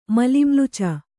♪ malimluca